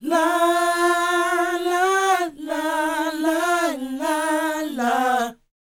NA-NA A#A -L.wav